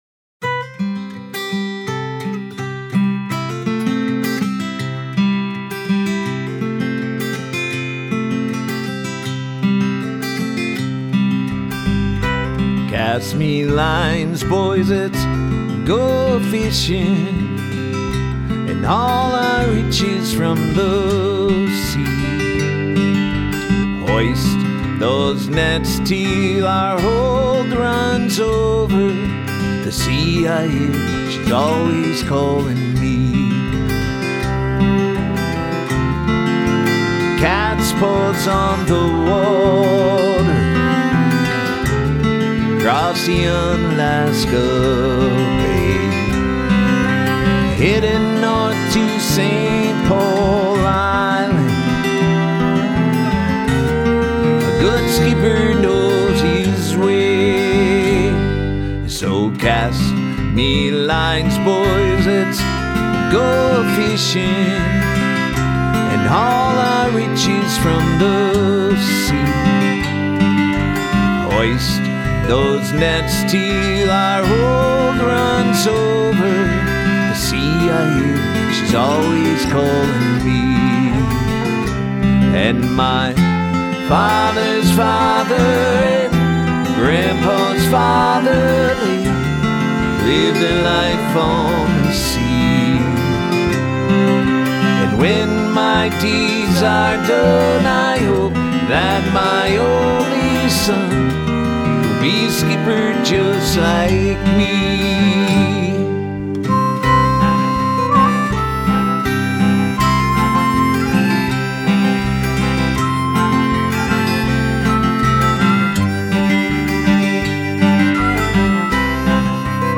Minnesota Folk Singer and Songwriter